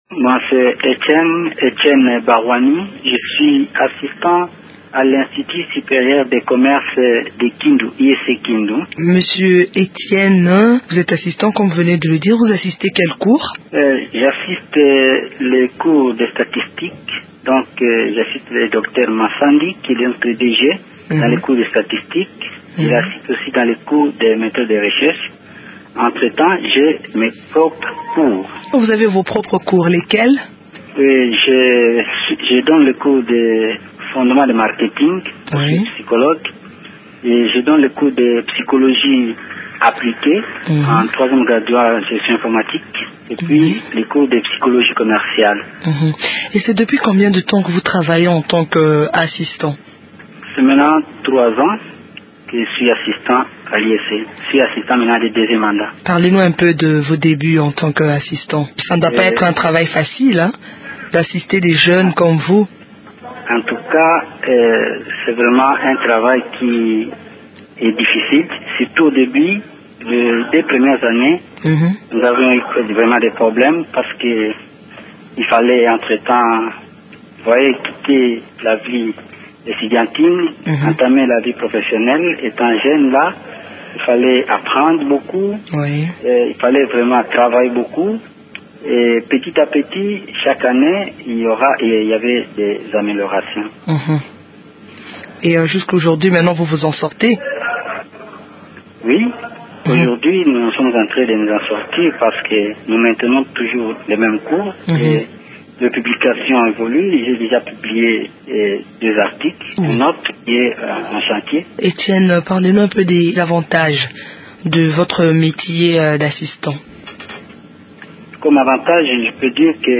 entretient téléphonique